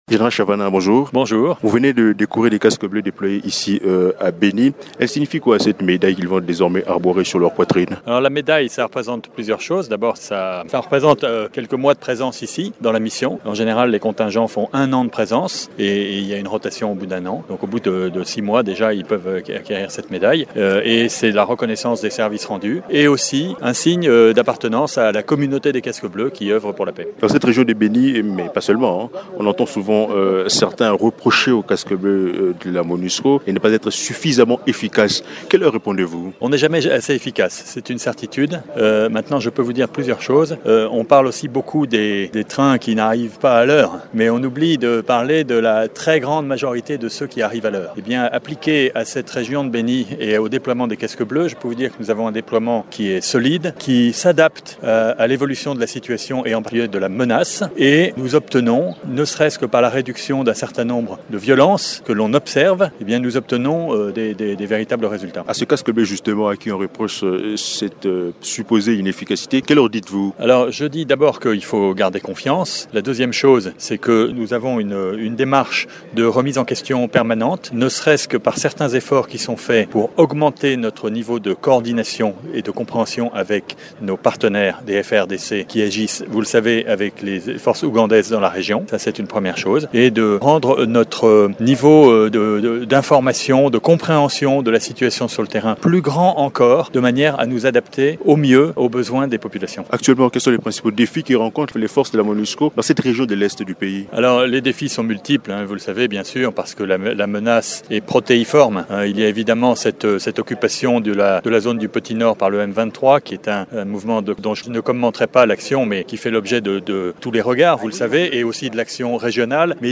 Invité de Radio Okapi, Général Benoît Chavanat reconnait toute fois que la situation dans l’Est de la RDC est complexe avec la présence de plusieurs forces militaires.